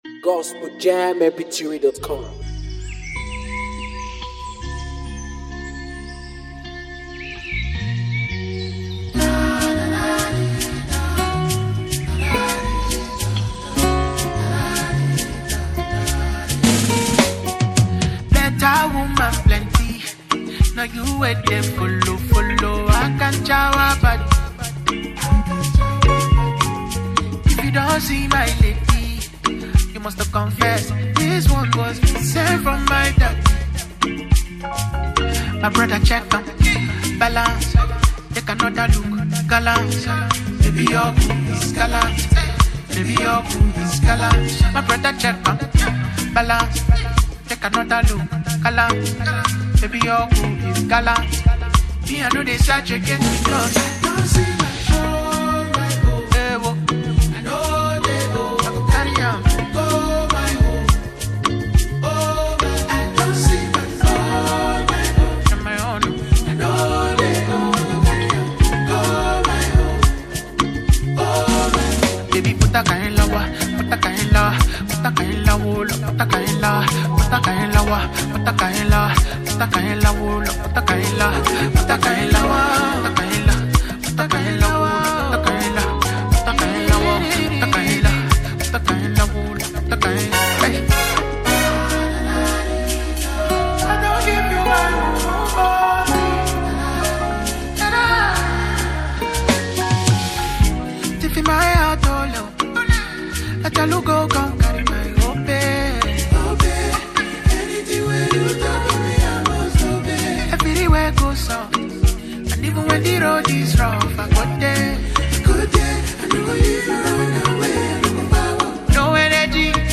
With strong vocal delivery and poetic imagery